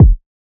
Kick (4).wav